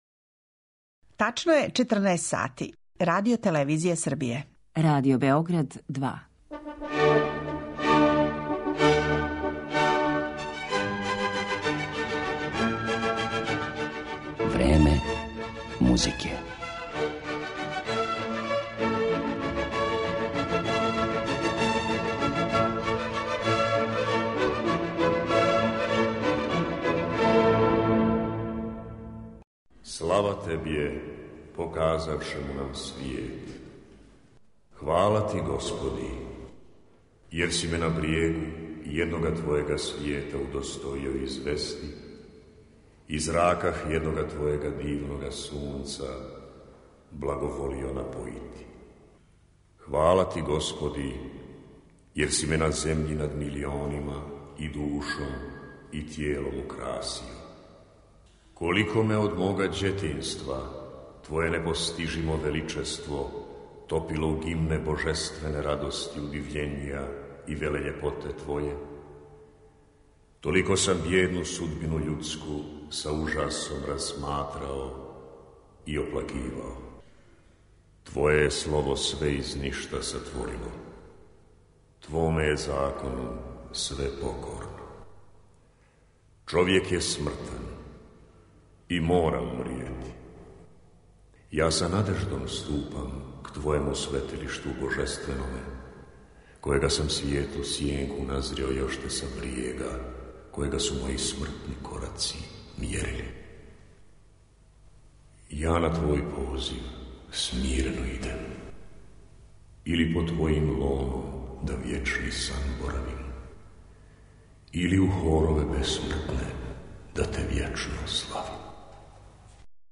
а слушаћете одломке композиција Рајка Максимовића, Николе Херцигоње, Жарка Мирковића и Љубице Марић.